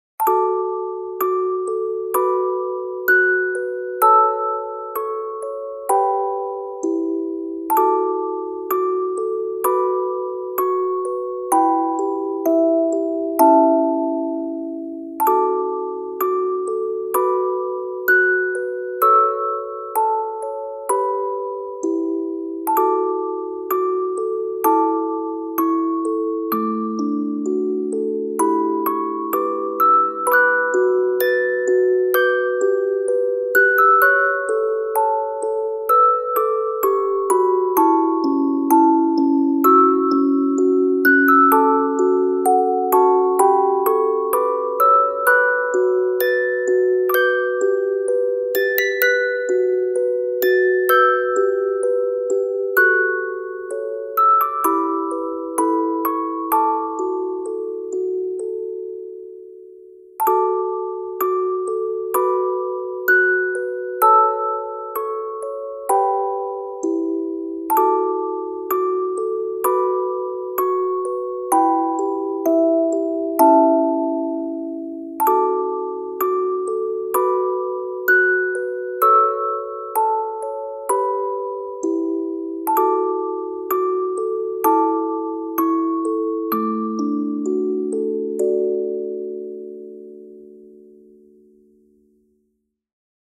静かな雰囲気のオルゴールBGMです。